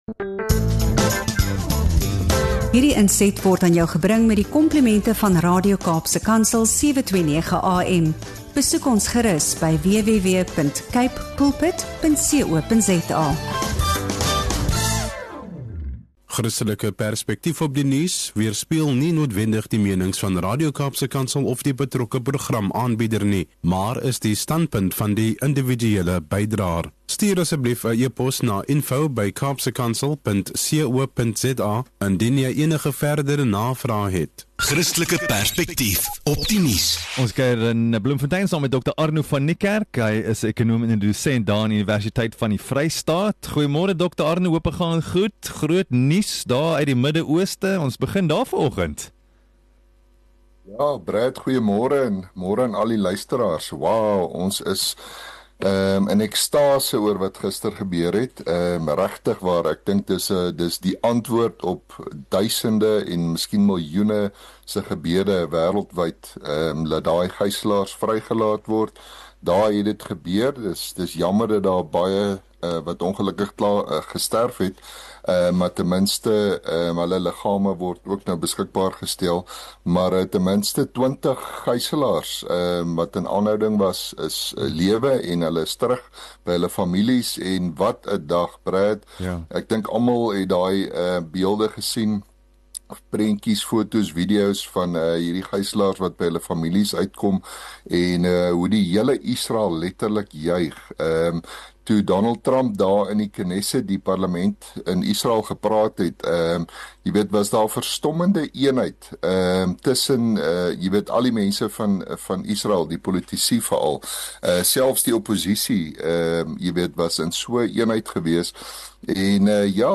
In hierdie insiggewende onderhoud